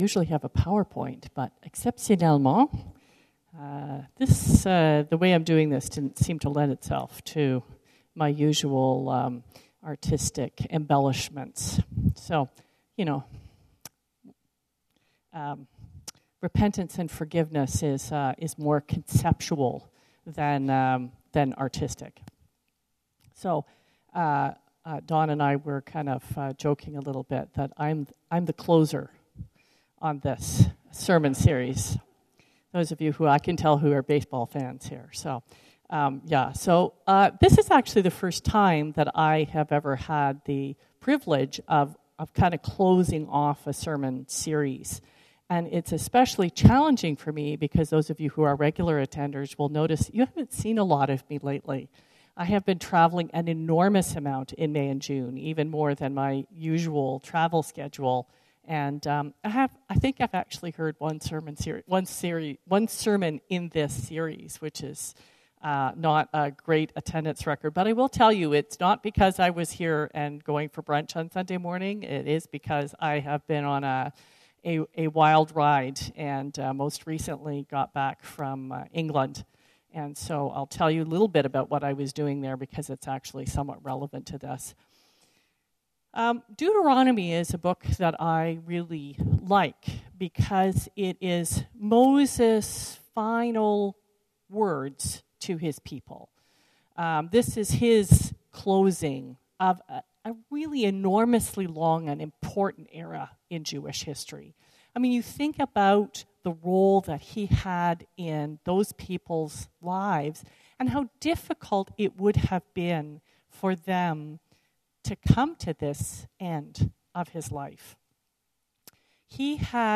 This sermon is based on Deut. 30.